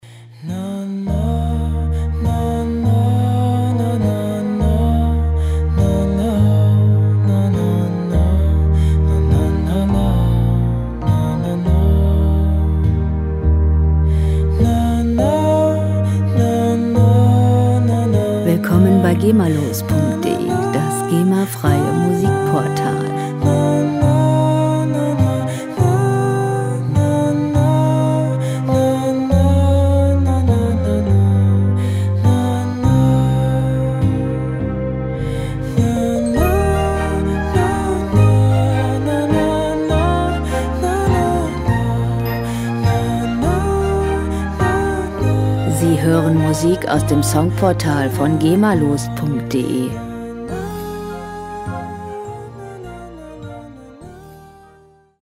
• Crossover